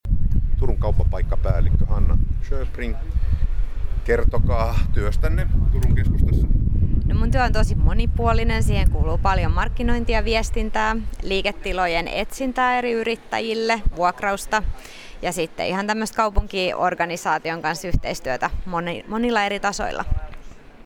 Paikka oli Forum korttelin keskusaula.